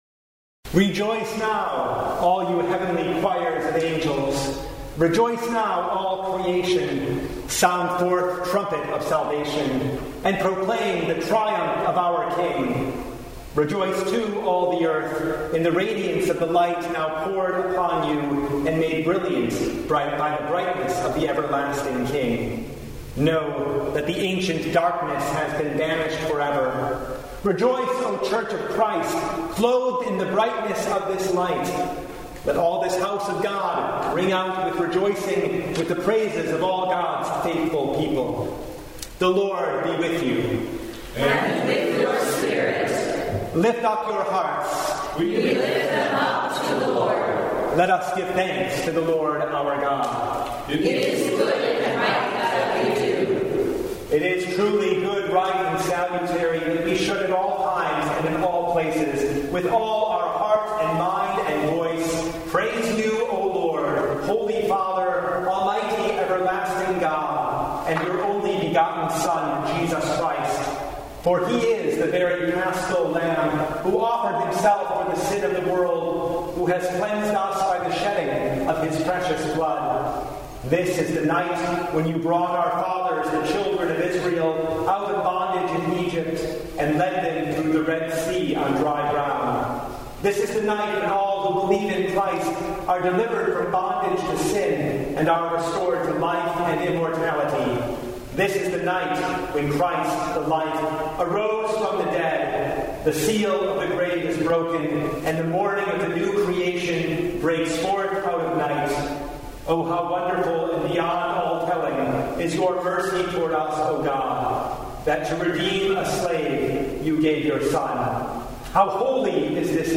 Full Service « Mount Sinai to Mount Calvary He Has Risen!